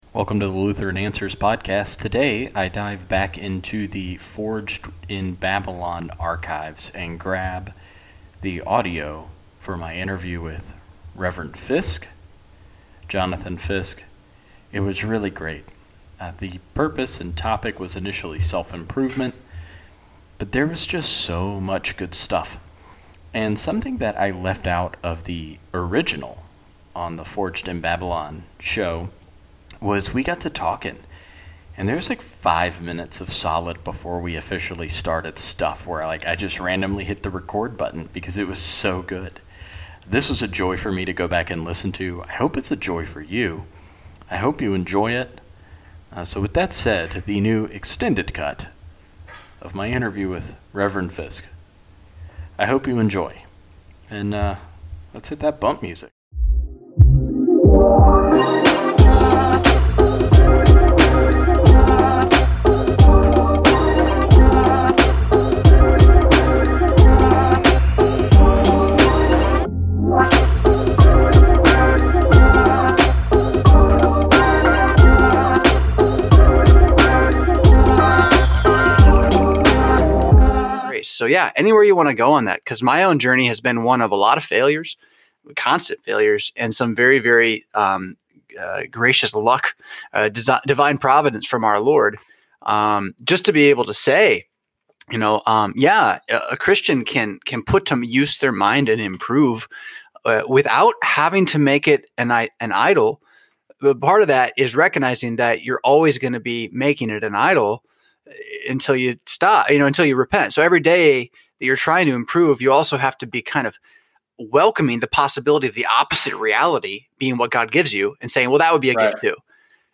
We set up a time and date and I interviewed him about self-improvement in Christianity.